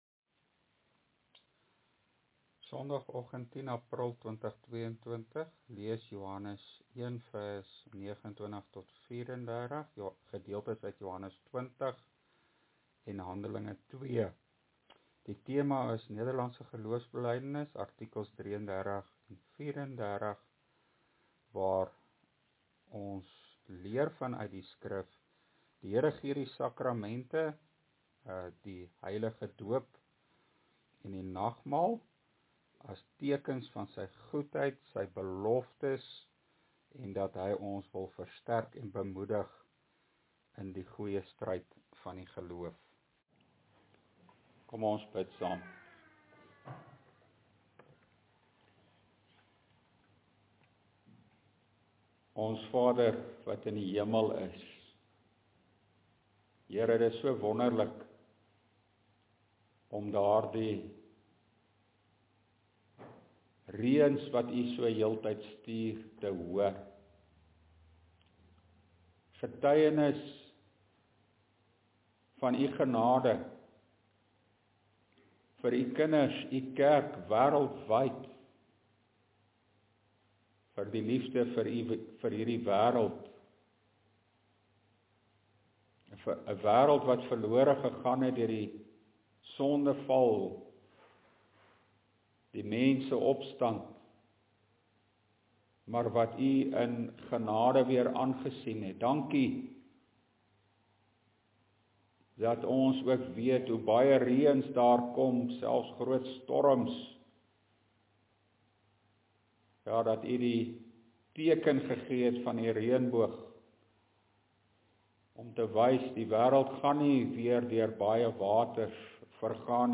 LEER PREDIKING: NGB artikel 33 – Die sakramente
” (Joh. 1:32–34) Tema: Die sakramente getuig en roep uit: Daar is die Lam van God! (Preekopname: GK Carletonville, 2022-04-10 , nota: let wel, die inhoud van die preek en teksnotas hier onder stem nie altyd ooreen nie, die notas is nie volledig nie, die audio preek is die volledige preek).